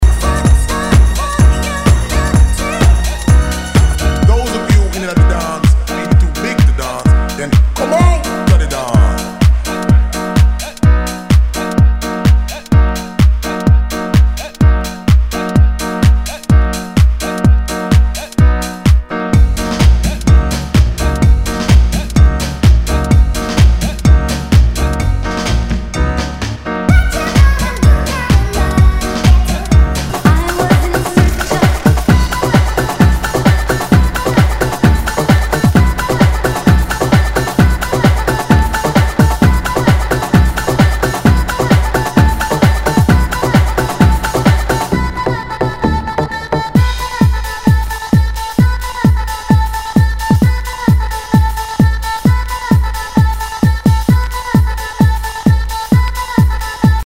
HOUSE/TECHNO/ELECTRO
ディープ / プログレッシブ・ハウス・クラシック！
全体にチリノイズが入ります